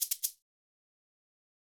KIN Beat - Shaker.wav